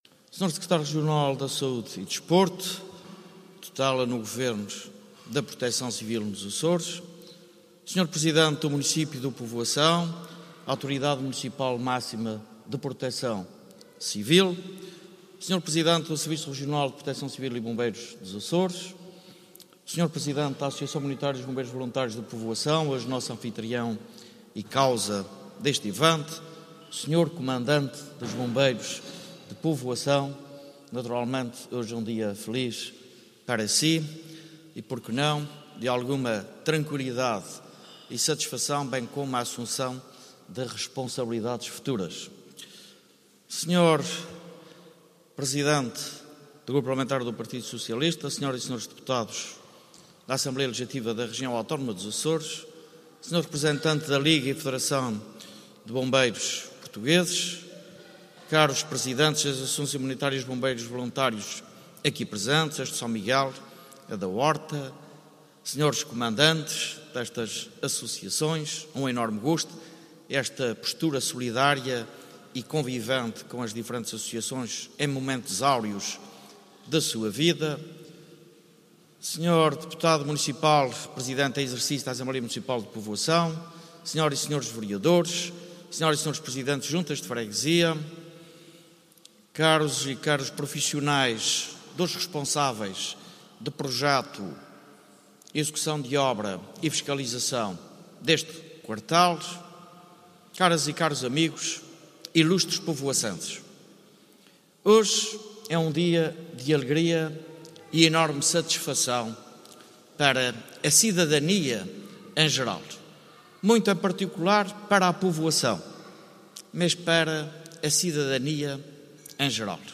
José Manuel Bolieiro falava na Povoação, na inauguração do novo quartel da Associação Humanitária dos Bombeiros Voluntários do concelho micaelense, investimento de 2,8 milhões de euros e garantia de “magníficas instalações” que representam uma melhoria significativa na capacidade operacional e no grau de prontidão destes bombeiros.